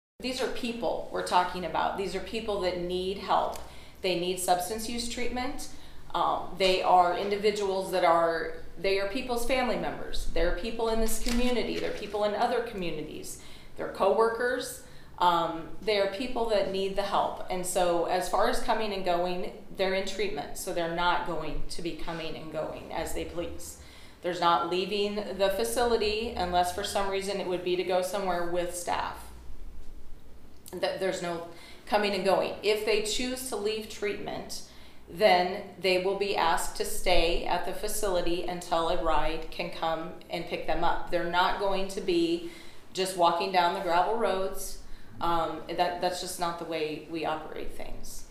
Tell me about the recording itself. (Cass Co.) The lease agreement for the old Willow Heights residential facility was the main topic of discussion at the Cass County Board of Supervisors meeting again this morning.